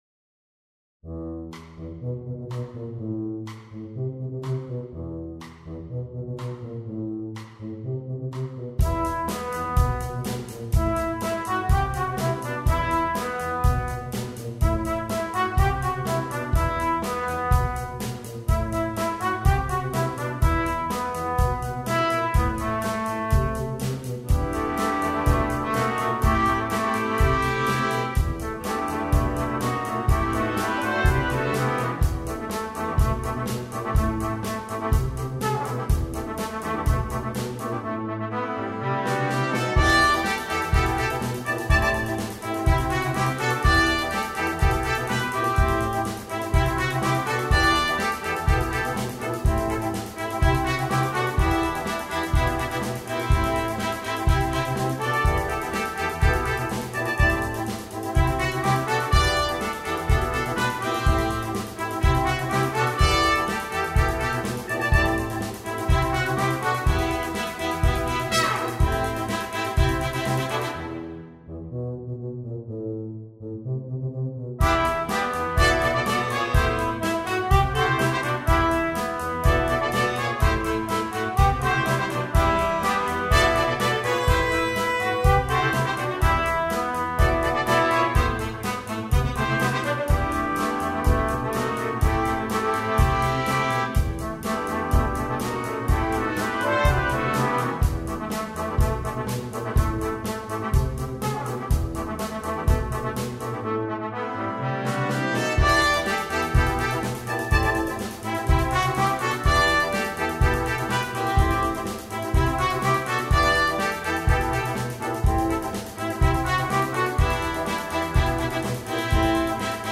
для брасс-бэнда.